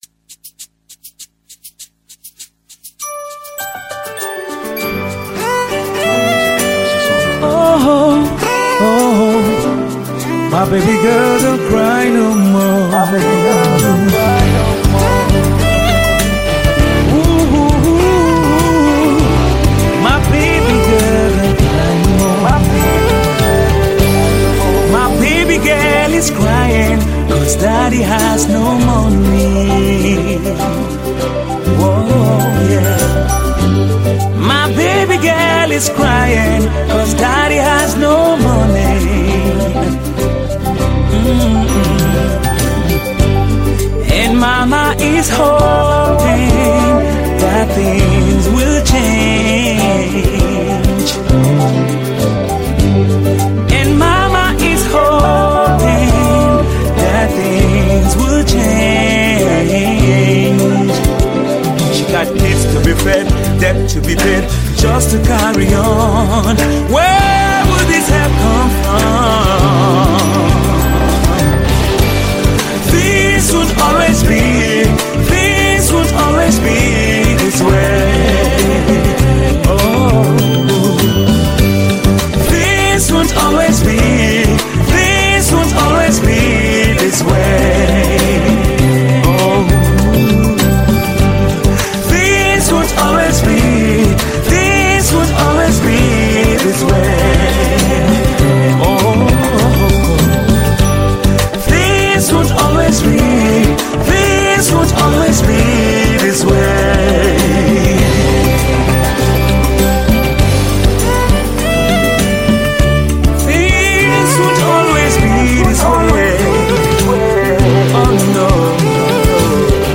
Tiv songs
encouraging, uplifts the spirit and soul